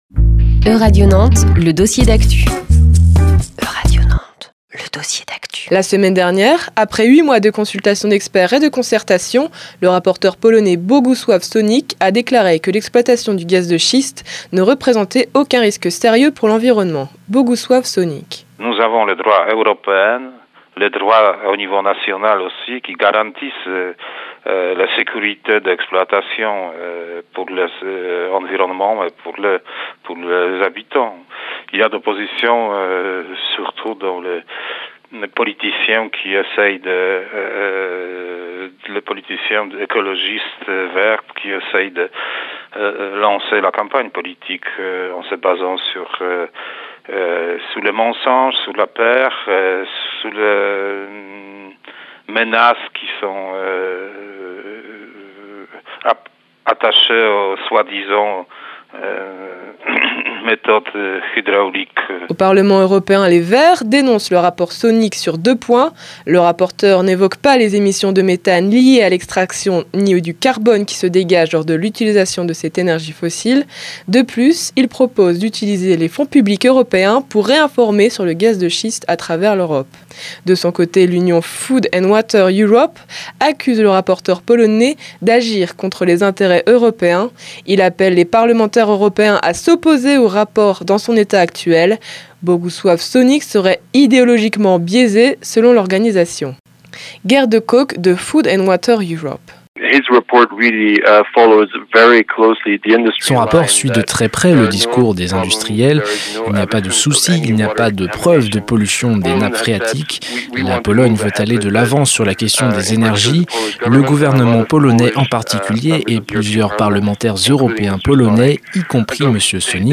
Reportage / documentaire